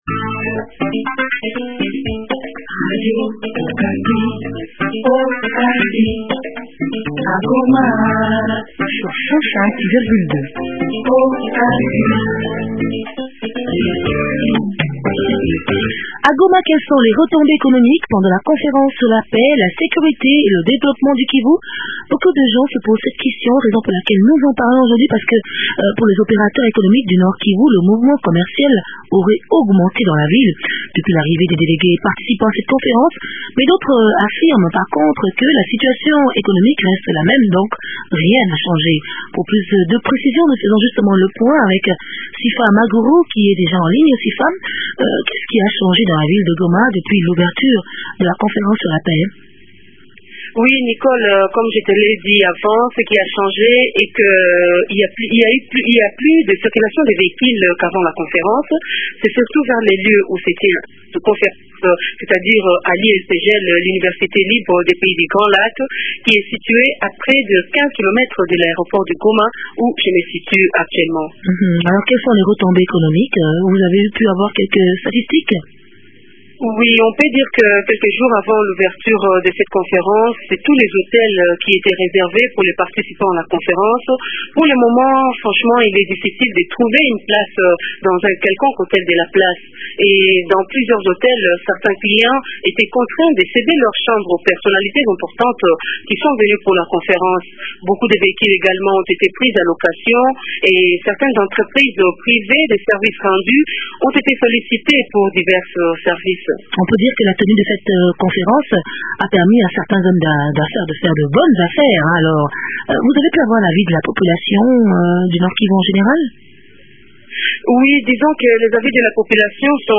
Mais entre –temps les répercussions économiques se font déjà sentir. Le mouvement commercial a augmenté dans la ville, depuis l’arrivée des délégués et participants à cette conférence. Le point dans cet entretien